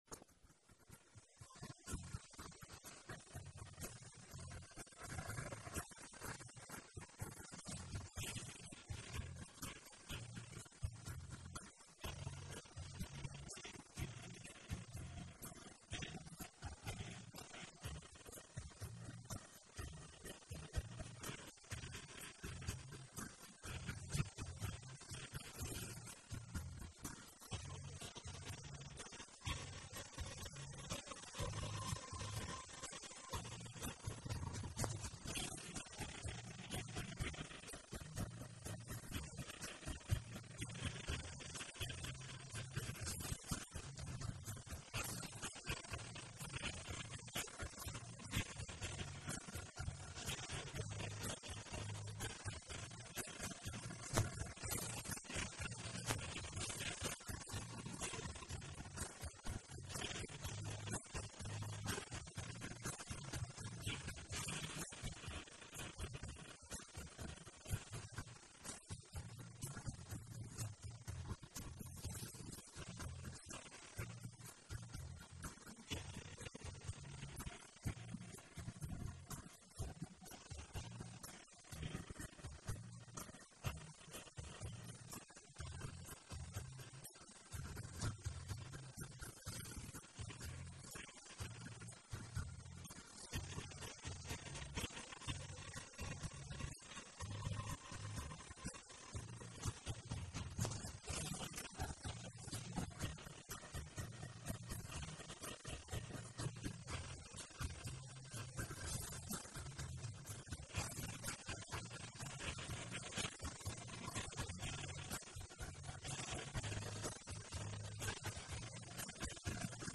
выполненная в жанре народной музыки.